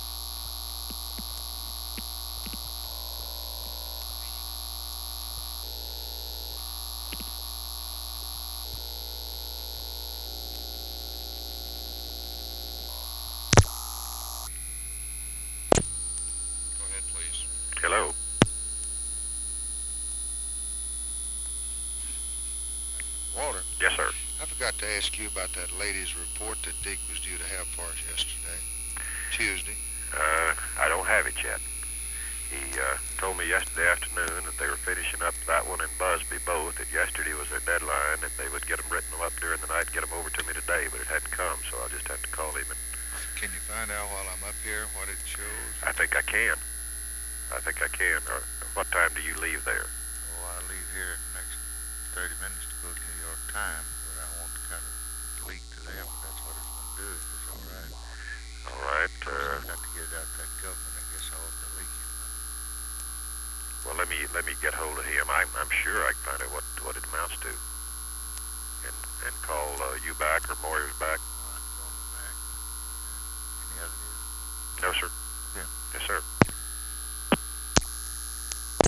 Conversation with WALTER JENKINS, February 6, 1964
Secret White House Tapes